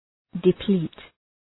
Προφορά
{dı’pli:t}